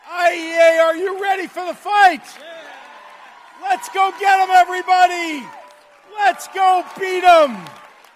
Pritzker addressed the Illinois Education Association Representative Assembly in Rosemont Thursday.